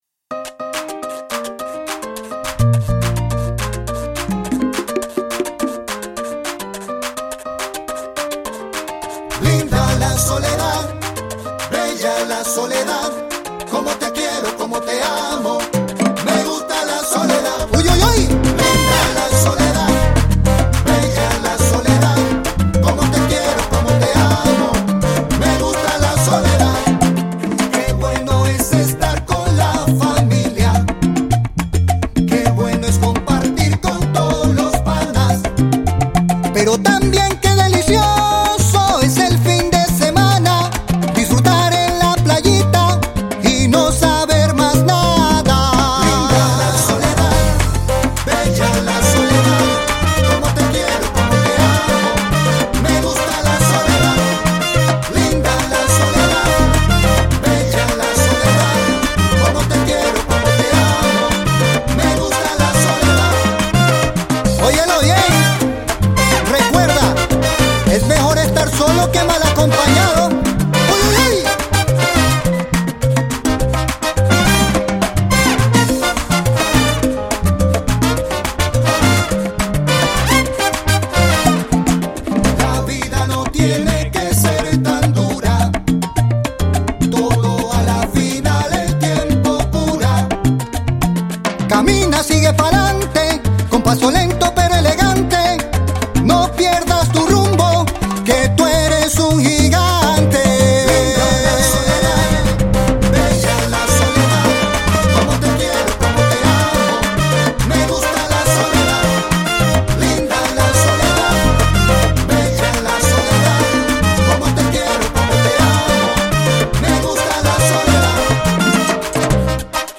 Salsa Tropical